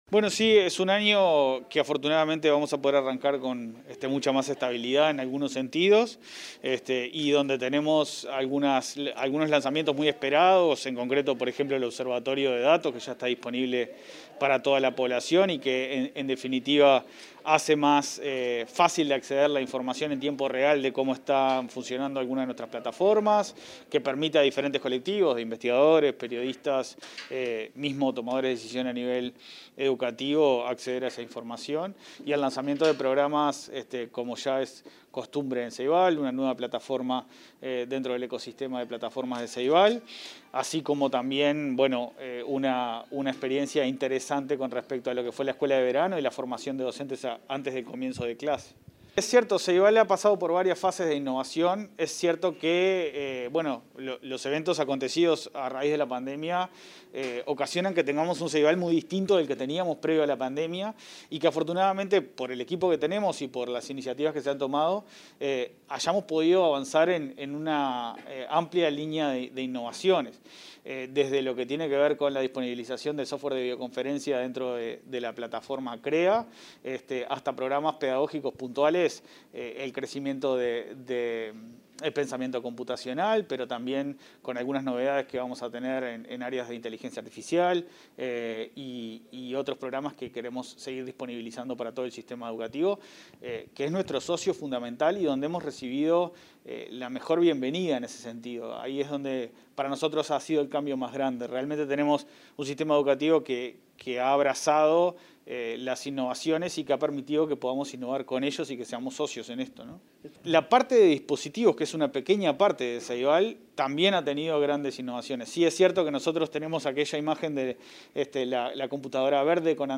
Declarlaciones a la prensa del presidente del Plan Ceibal